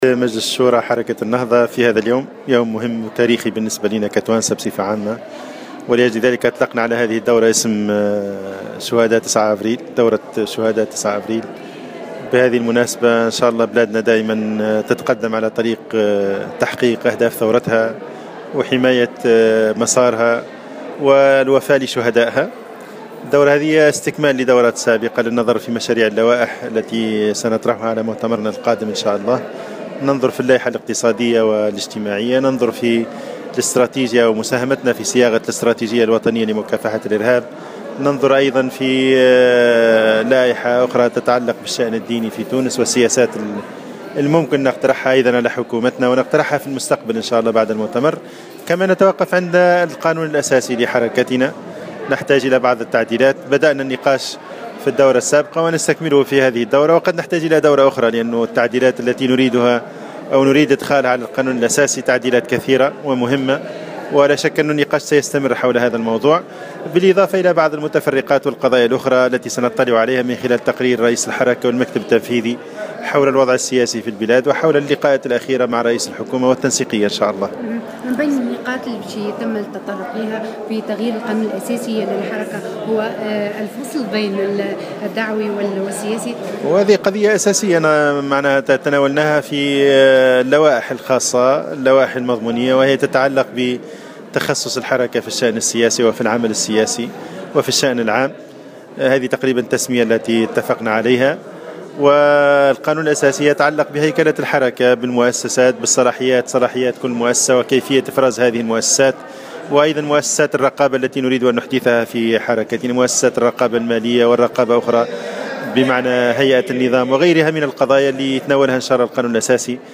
واكد في تصريح لـ "الجوهرة أف أم" على هامش اجتماع لشورى النهضة في الحمامات، أن الفصل بين الدعوي والسياسي، كان من بين القضايا الأساسية التي تم تناولها في إطار إعداد اللوائح المضمونية للمؤتمر القادم للحركة بالاضافة إلى وضع إستراتيجية لمكافحة الإرهاف.